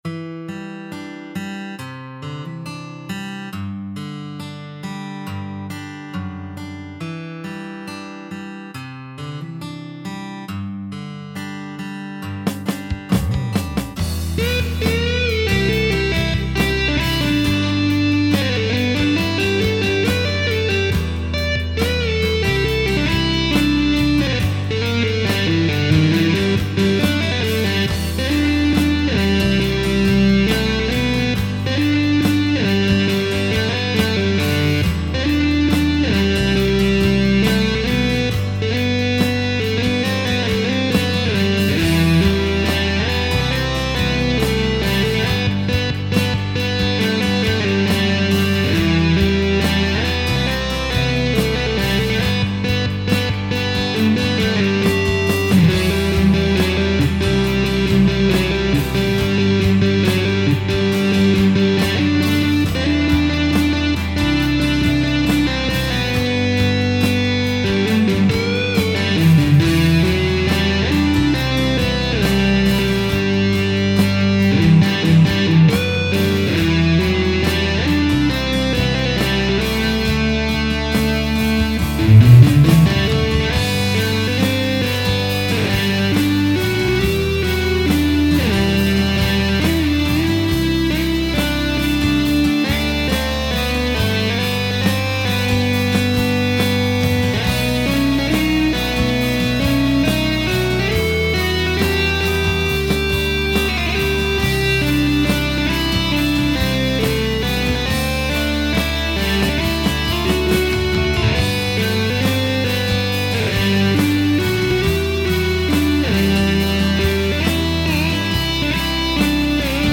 谱内附带：GTP谱【有配GP8版本】、PDF曲谱、MP3音频伴奏、音频示范
谱内音轨：独奏电吉他